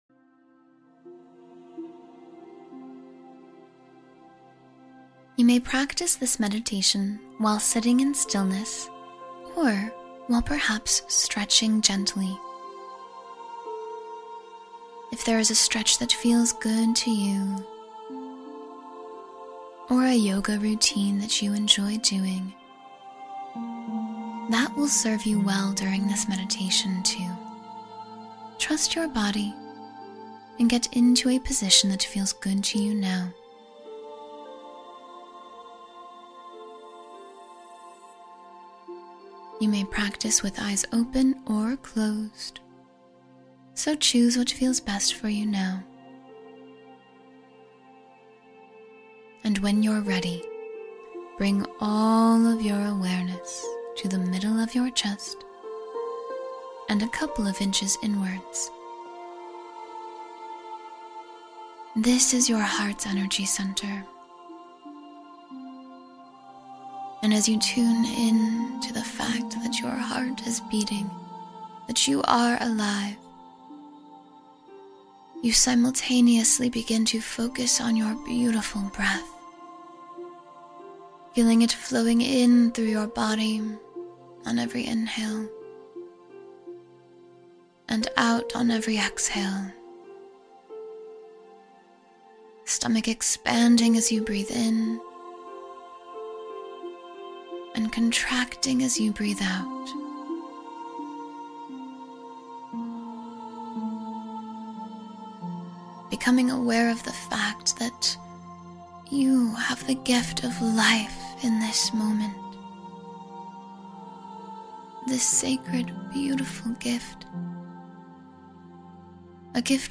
Feel the Gift and Beauty of Life — Meditation for Gratitude and Presence